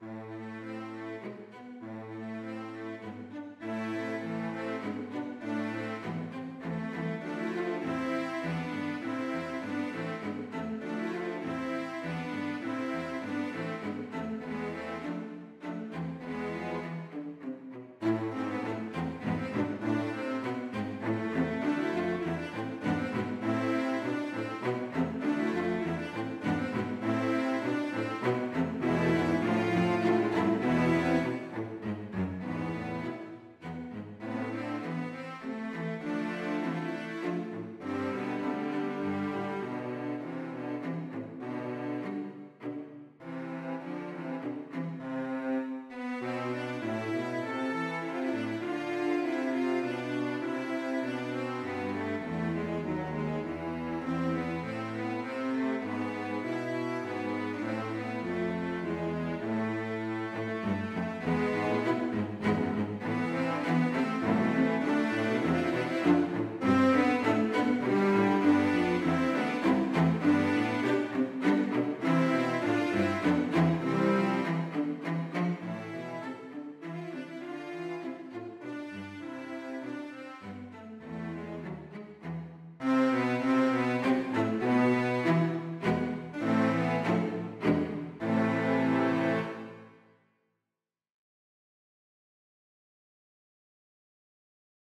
Lydfilene er digitalt produsert i noteprogrammet og er bare illustrasjoner.
• CELLOKVARTETT
- Bang 114b: Menue for cello kvartett   Note